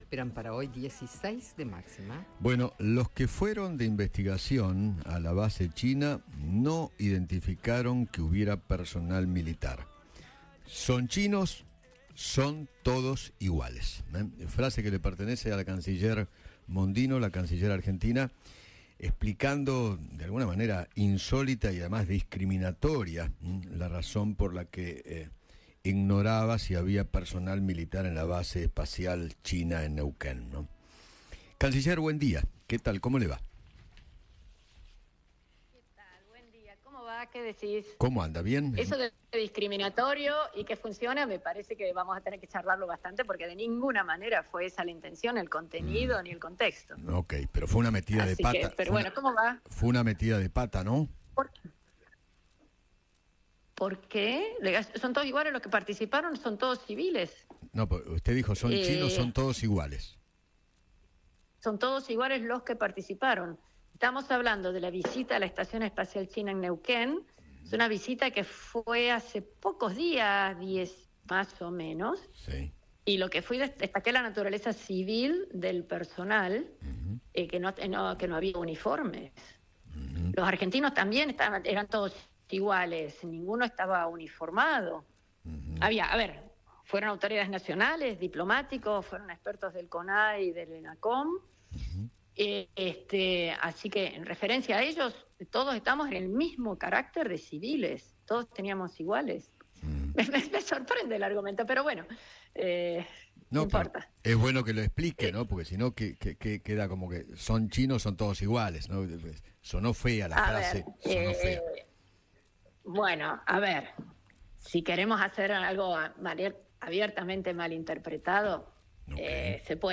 La canciller Diana Mondino habló con Eduardo Feinmann y aclaró qué quiso decir en la base en Neuquén.